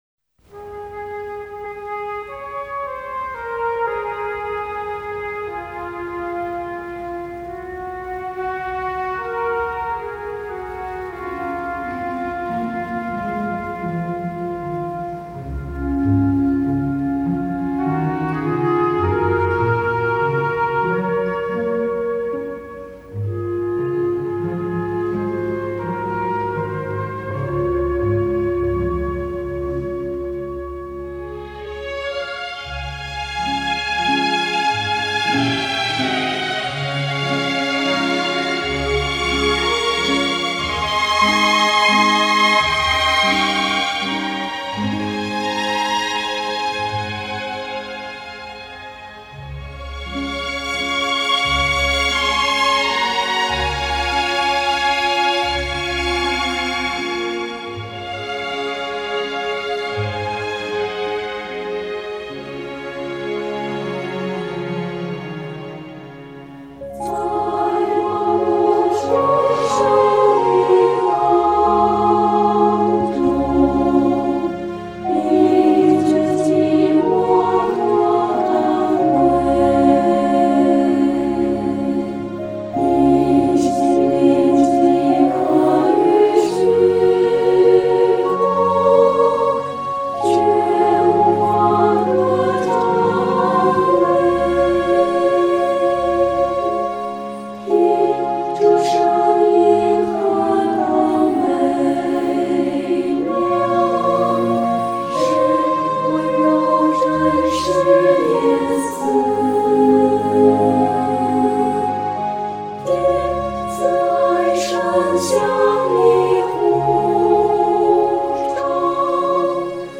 本首圣诗由网上圣诗班 (呼和浩特）录制